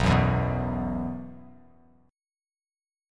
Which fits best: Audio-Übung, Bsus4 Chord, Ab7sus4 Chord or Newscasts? Bsus4 Chord